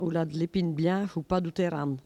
Mémoires et Patrimoines vivants - RaddO est une base de données d'archives iconographiques et sonores.
Patois
Catégorie Locution